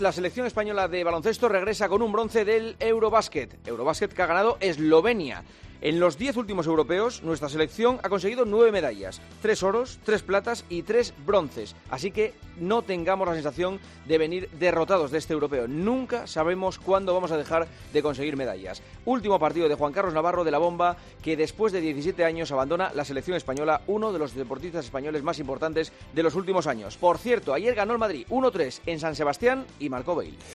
El comentario de Juanma Castaño
La Selección regresa con un bronce del EuroBasket, en el comentario de Juanma Castaño, director de 'El Partidazo de COPE', en 'Herrera en COPE'.